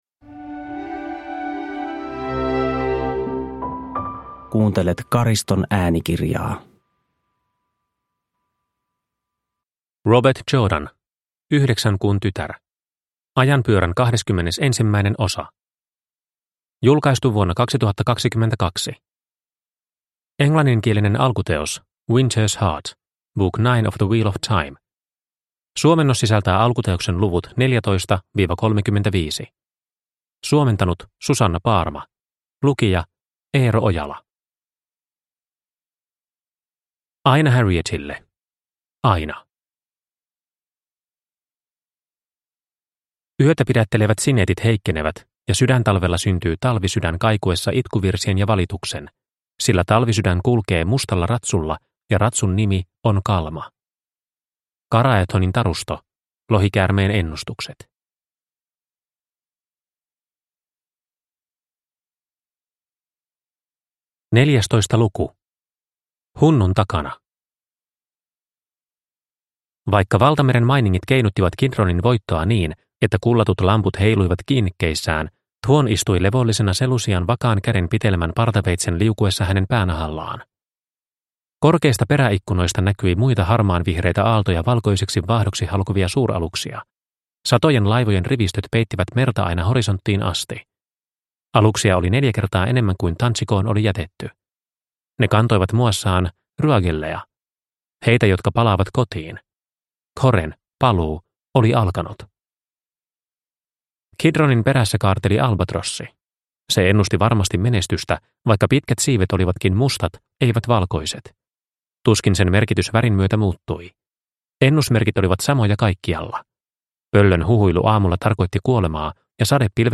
Yhdeksän kuun tytär – Ljudbok